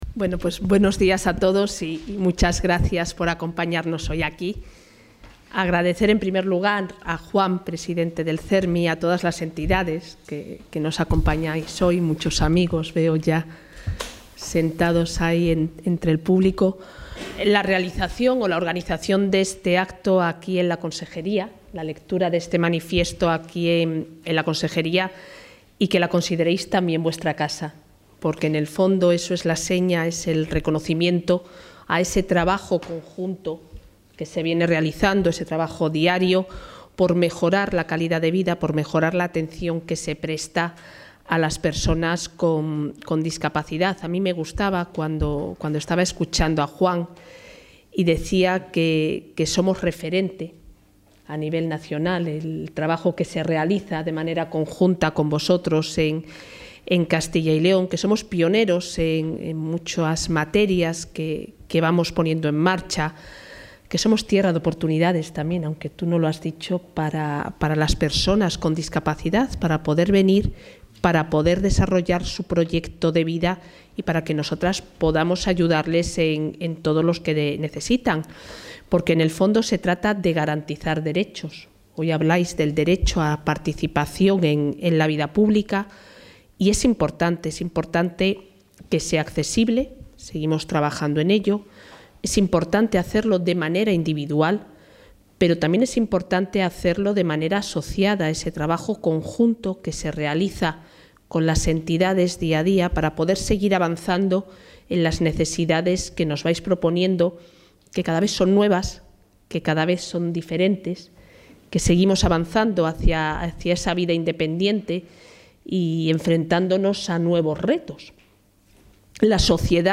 Intervención de la consejera de Familia e Igualdad de Oportunidades.
La consejera de Familia e Igualdad de Oportunidades, Isabel Blanco, ha participado hoy en el acto conmemorativo por el Día Nacional de la Convención Internacional sobre los Derechos de las Personas con Discapacidad organizado por Cermi CyL en Valladolid.